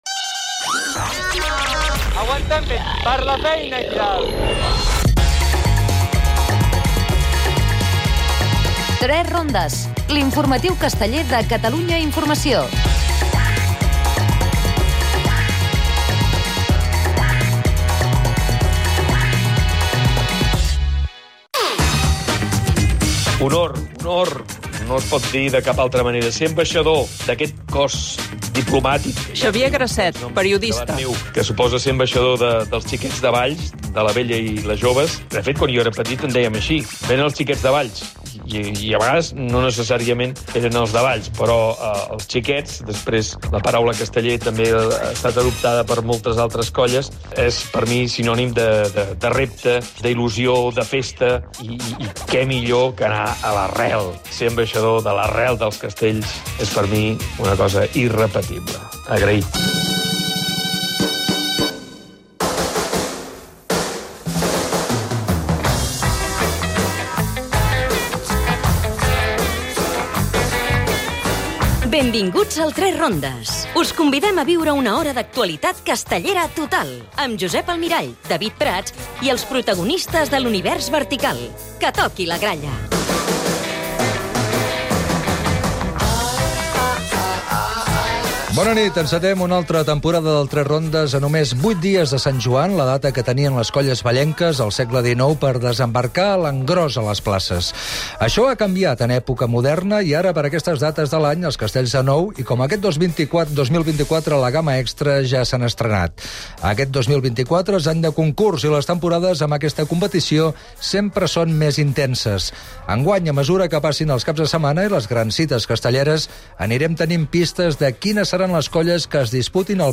El "3 rondes" ha estat a les diades de Sant Gervasi, La Grallada de Vilanova, la catedral de Barcelona i local de la Colla Jove Xiquets de Tarragona. Tertlia
Entrevista